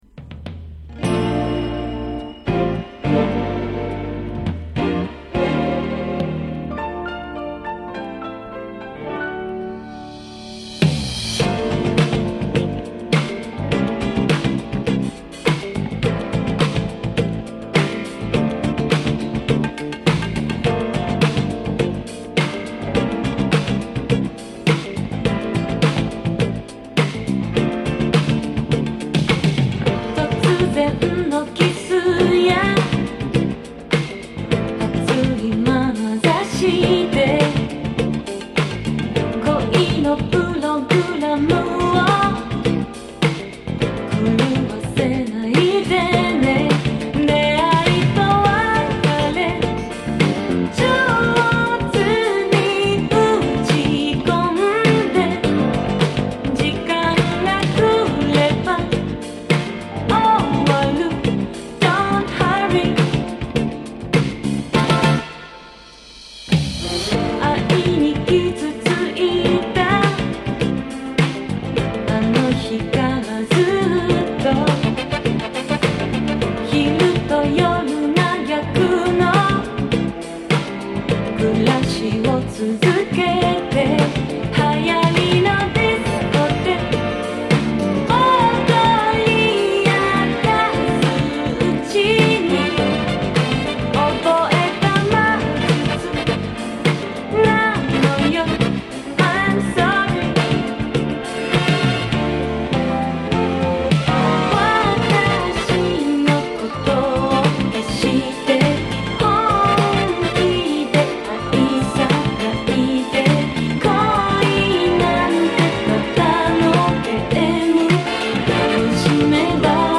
> 和モノ/JAPANESE GROOVE